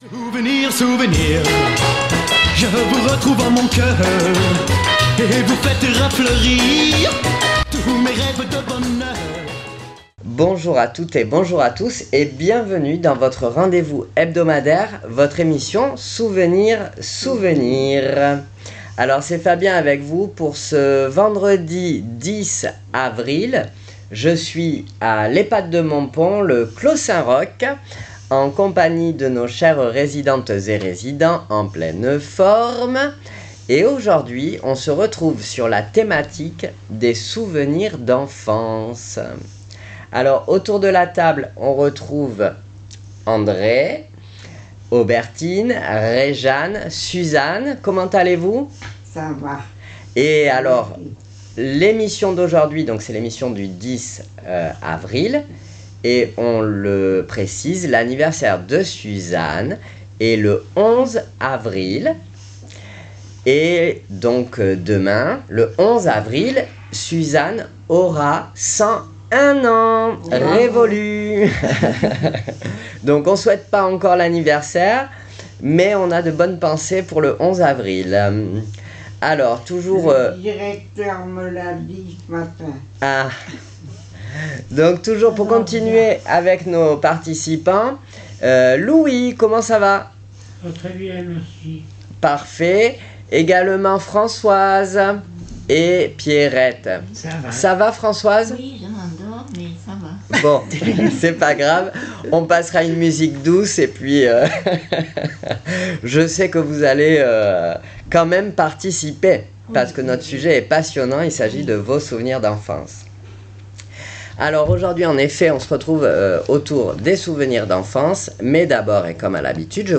à l'Ehpad de Montpon, Le Clos Saint Roch, avec nos chers résidents en pleine forme